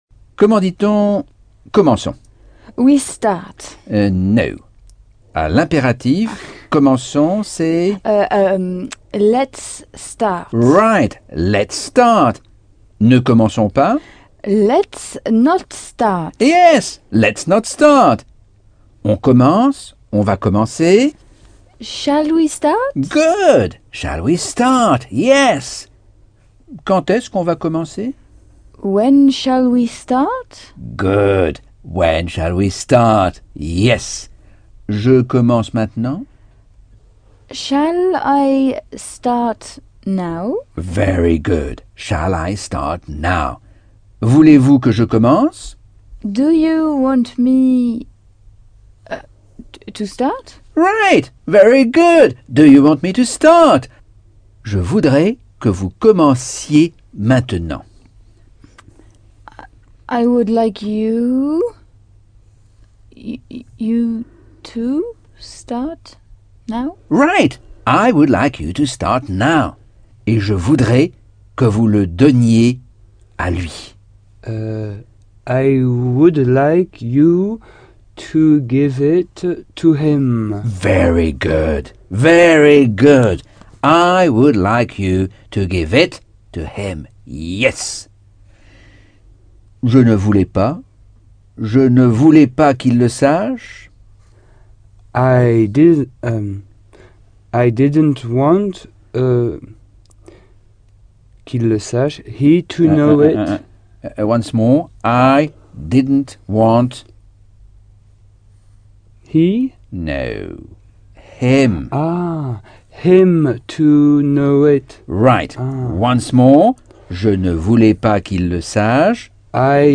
Leçon 4 - Cours audio Anglais par Michel Thomas - Chapitre 11